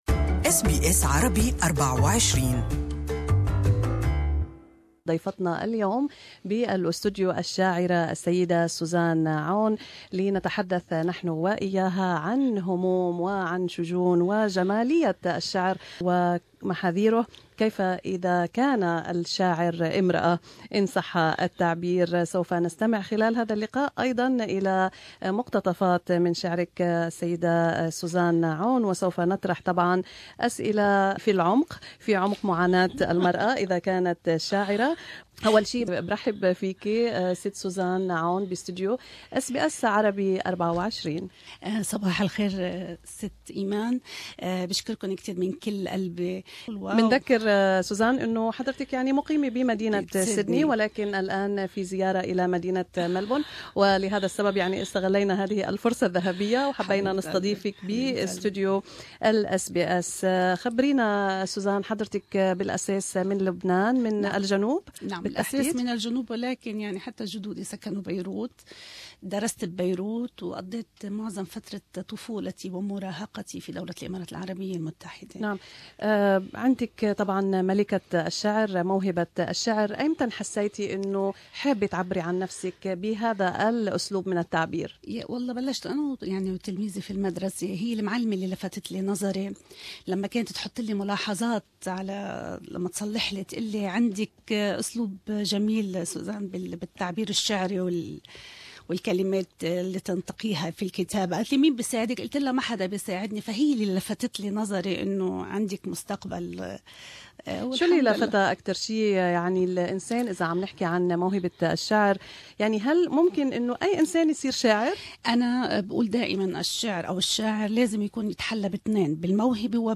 In this interview, she speaks about her dilemma of being a talented and vocal female poet and what's accepted in conservative circles.